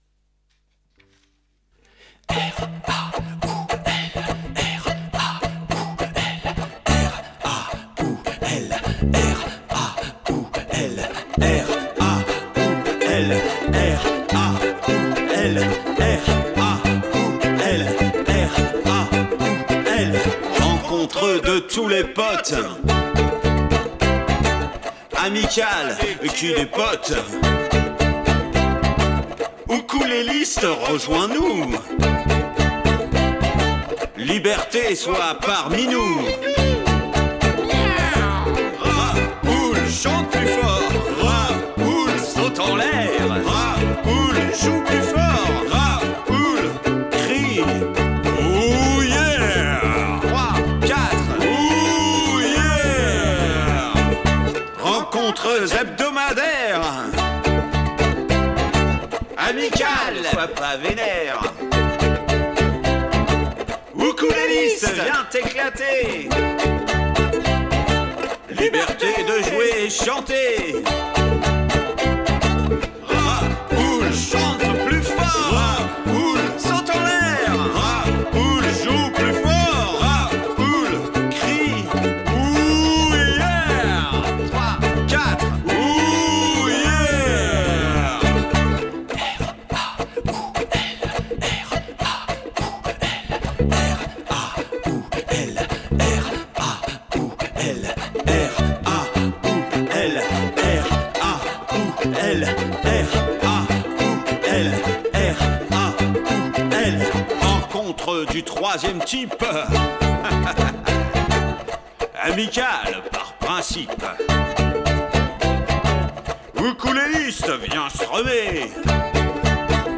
NC D D C/D Refrain : G D A D D D/G D voir les accords dans l'atelier analyse du texte Cette chanson apparaît dans le(s